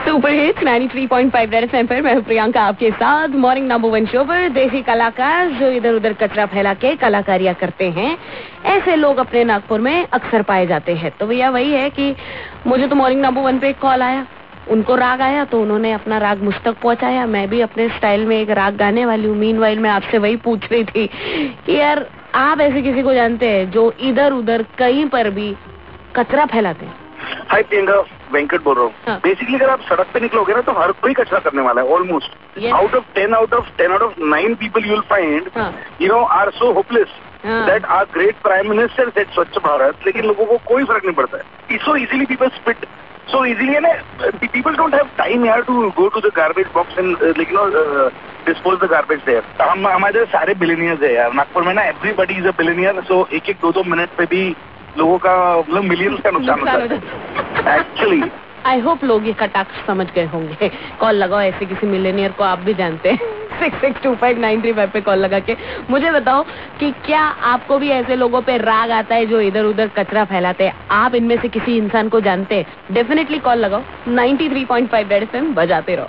27JUL_L10_CALLER INTERACTION